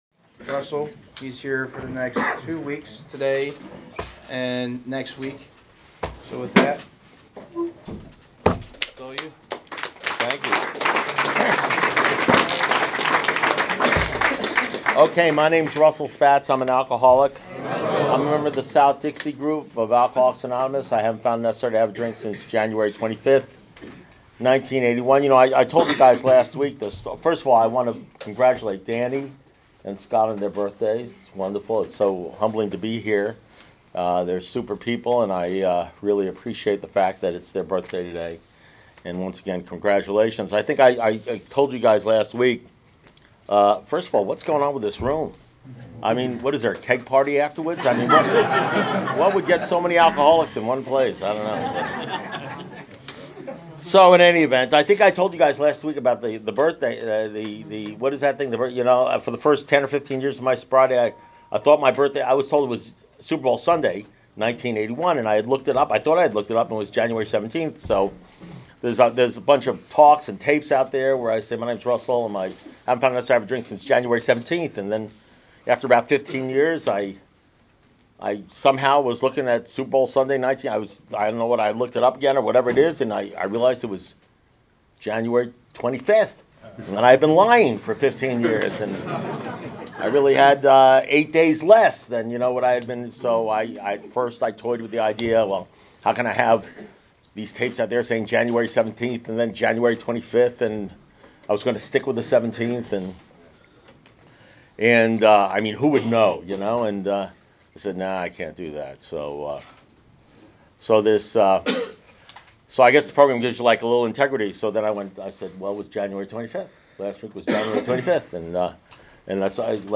Alcoholics Anonymous Speaker Recordings
Crossroads Club Delray Beach Florida 2018